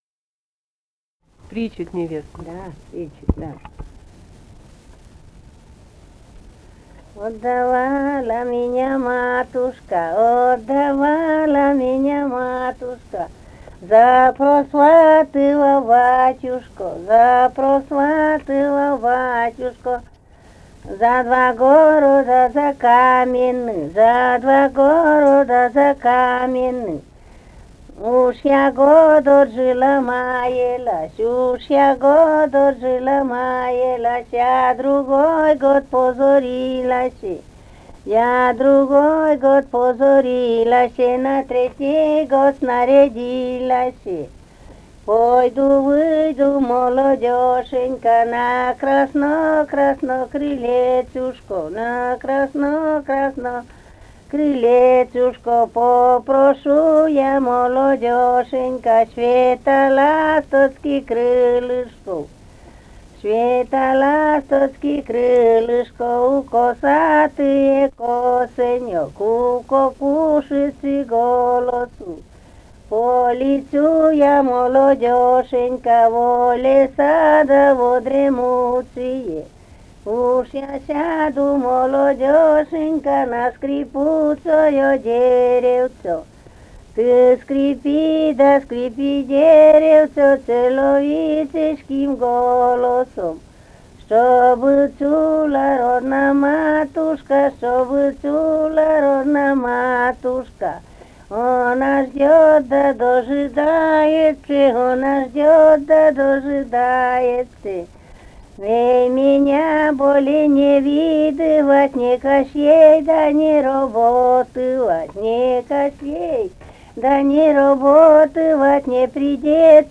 «Отдавала меня матушка» (причитание невесты).